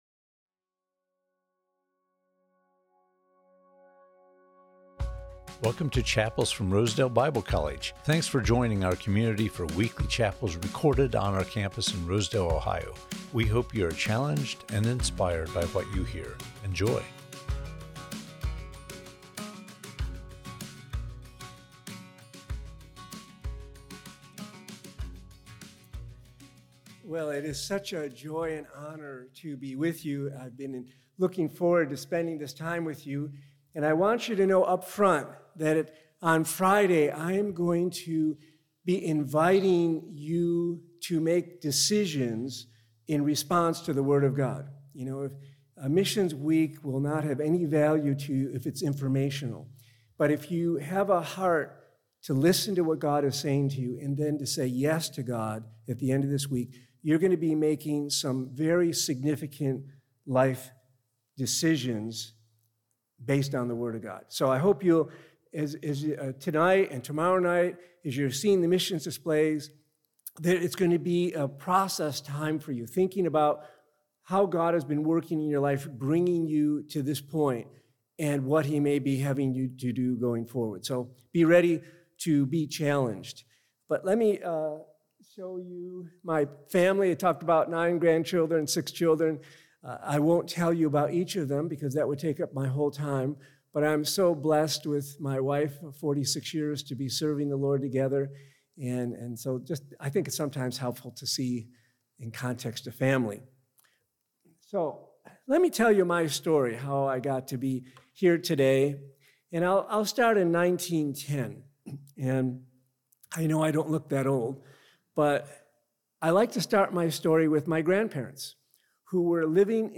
Chapels from Rosedale Bible College